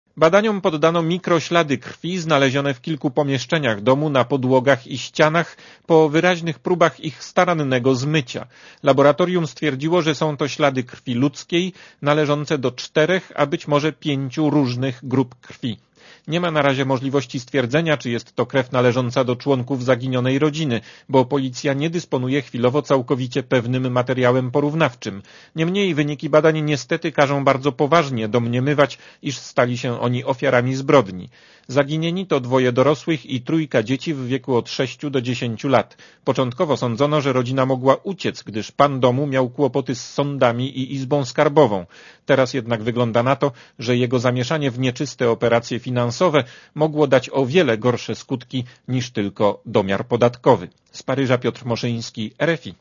Posłuchaj relacji korespondenta Radia Zet (208 KB)